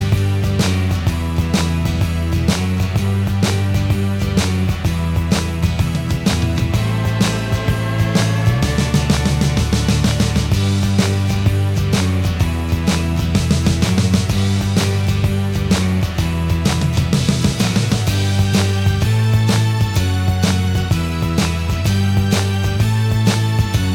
Minus Lead Guitar Glam Rock 2:30 Buy £1.50